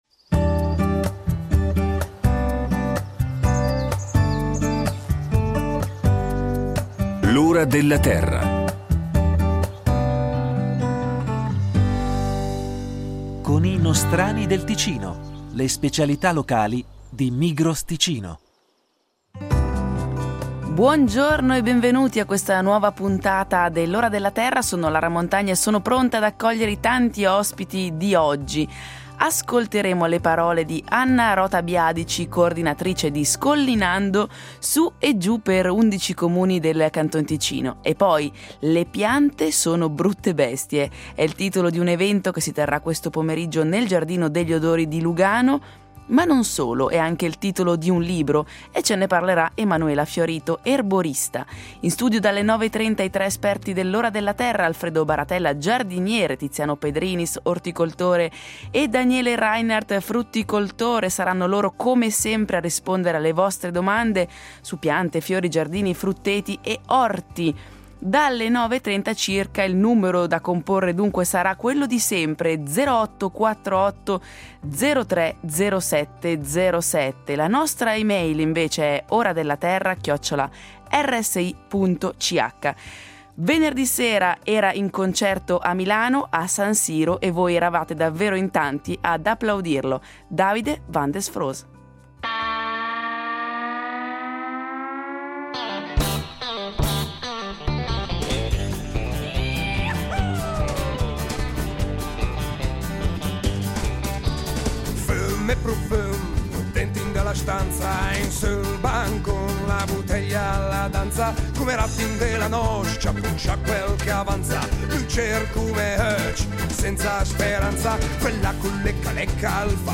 In studio i tre esperti de L’Ora della Terra, per rispondere alle domande del pubblico da casa su orto, giardino e frutteto.